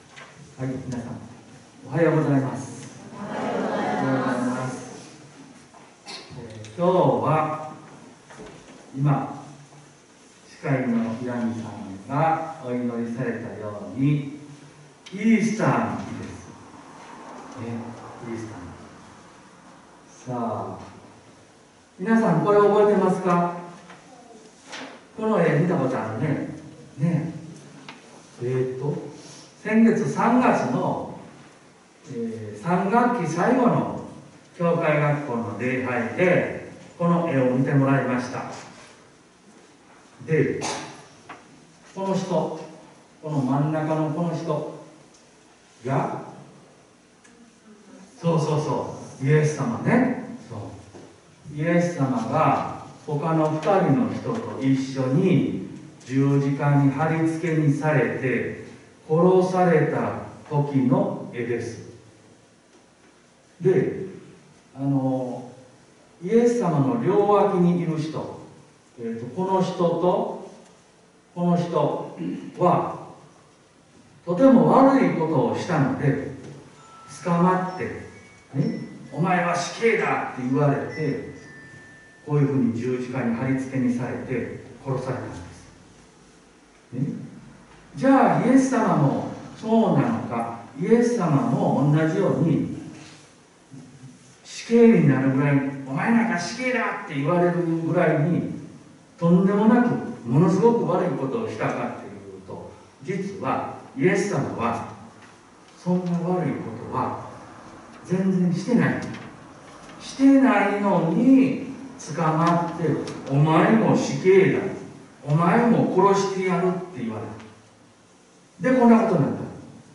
音声ファイル（説教部分のみ）